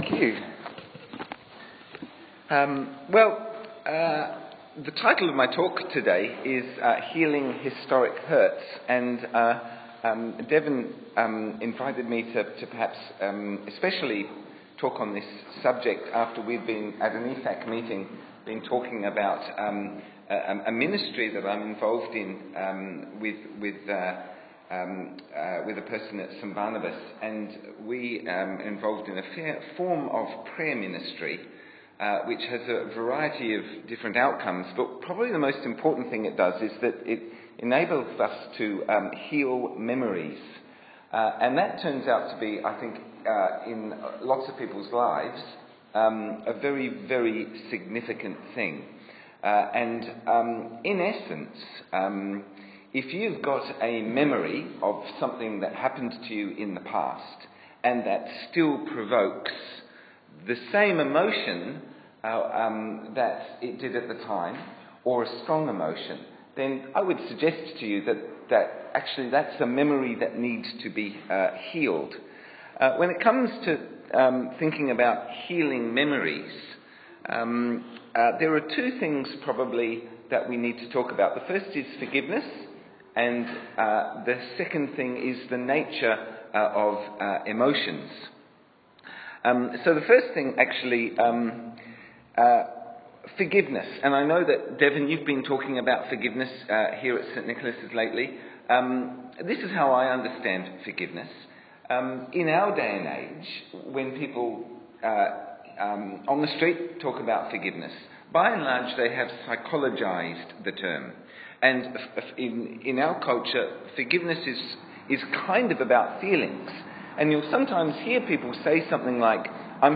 Series: Men’s Breakfast